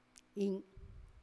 次の話者の発音を聞いてみてください。
また、今回の我々のデータは、宮古語池間方言を話す一部の地域の話者（西原地区の話者）、しかも７０歳以上の話者のデータしか扱っていないことも特記に値するでしょう。
sea_005_KA_word.wav